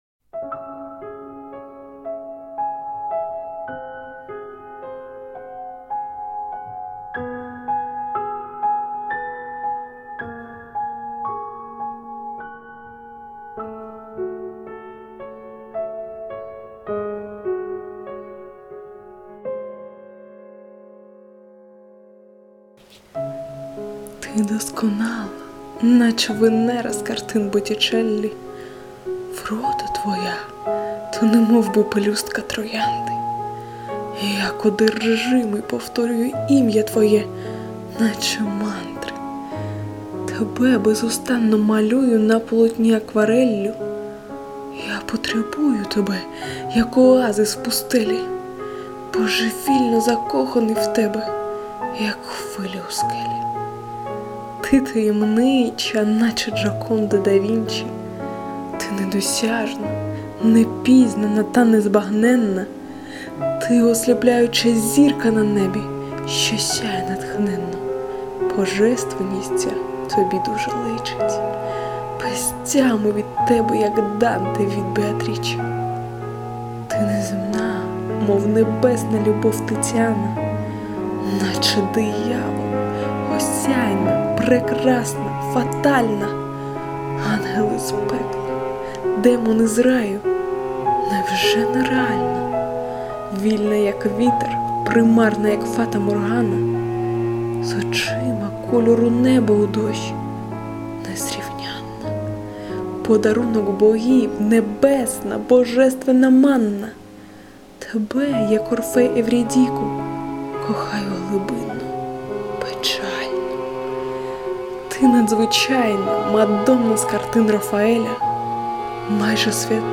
1 Досконале читання перекреслив шурхіт паперу у самому кінці зпису голосу.
2 Добре б зробити компресію звуку, бо деякі склади зникають, тонуть у музичному супроводі, котрий на 3-4 дБ варто понизити.
На счёт шелеста, то его я научилась убирать, так как это не из-за бумаги, а из-за выключения аппаратуры, на которую я записываю.
Чудова поезія і чаруючий голос...